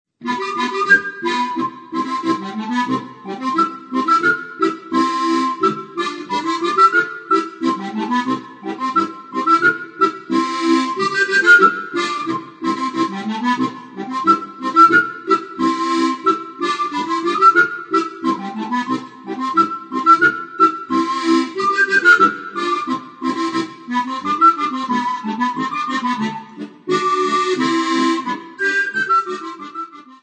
Besetzung: Okarina und Steirische Harmonika